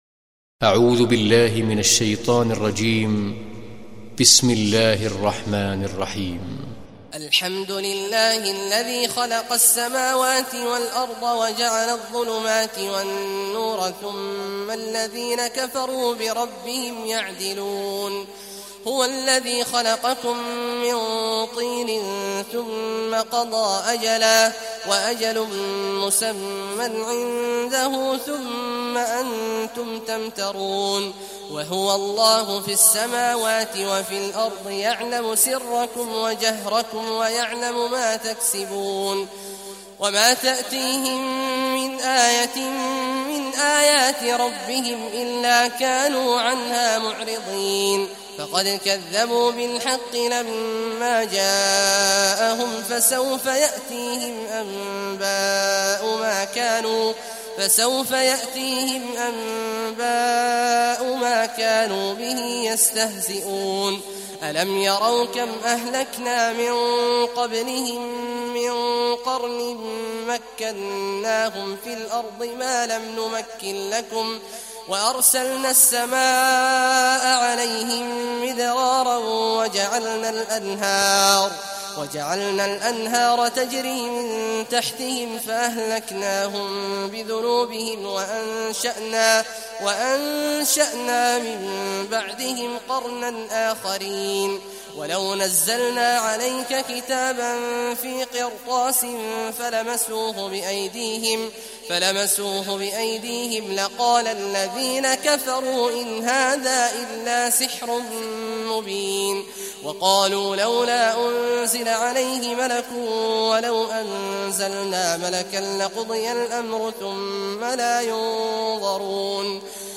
Sourate Al Anaam Télécharger mp3 Abdullah Awad Al Juhani Riwayat Hafs an Assim, Téléchargez le Coran et écoutez les liens directs complets mp3